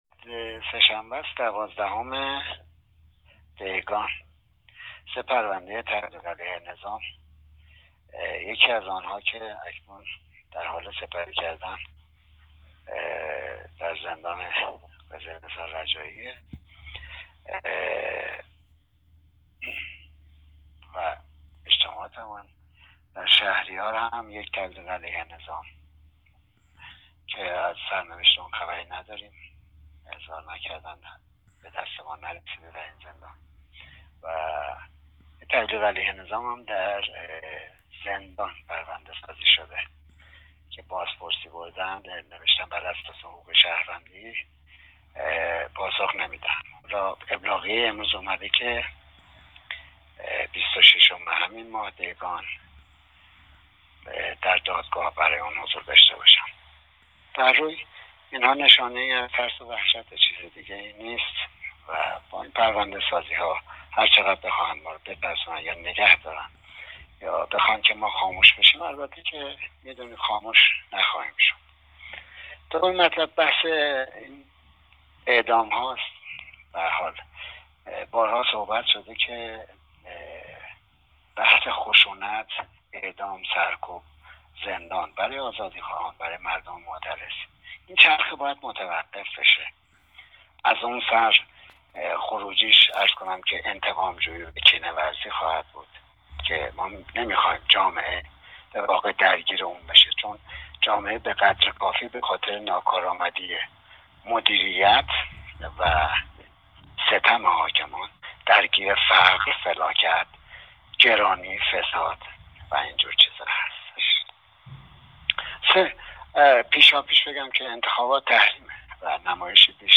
در پیامی تلفنی